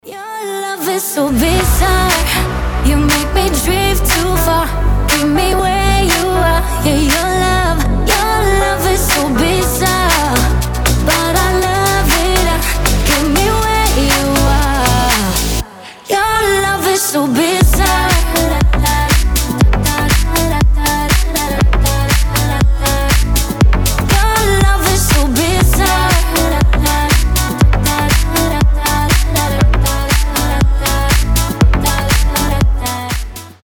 • Качество: 320, Stereo
Dance Pop